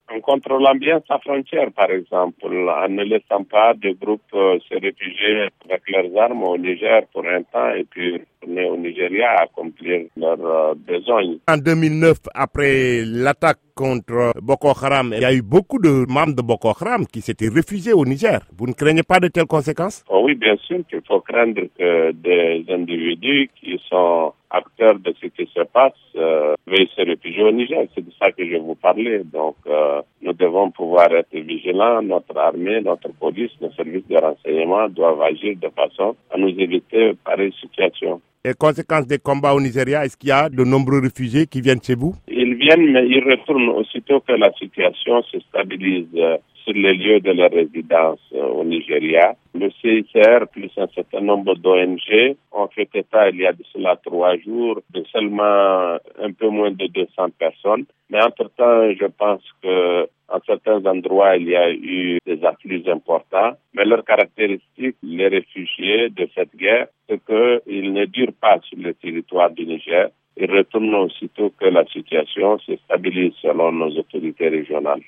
Mohamed Bazoum, ministre des Affaires étrangères du Niger, parle de l'aide que son pays peut apporter au Nigeria dans la lutte contre Boko Haram.